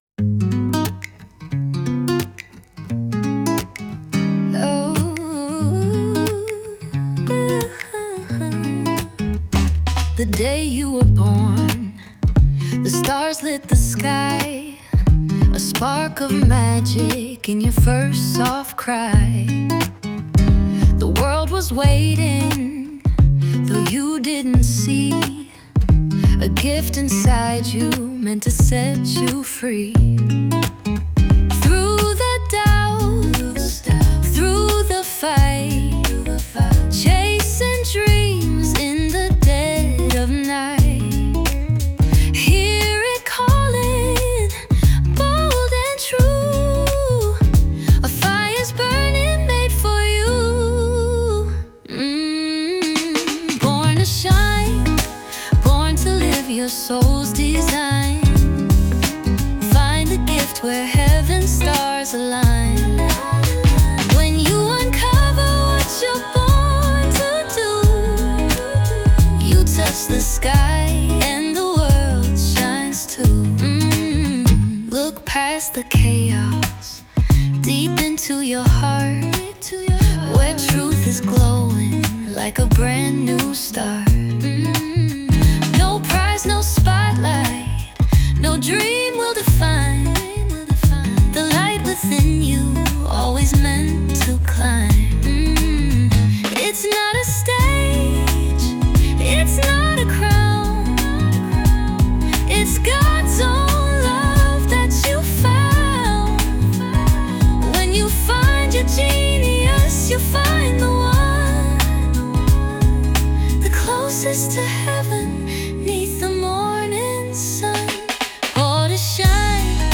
Jazz, R&B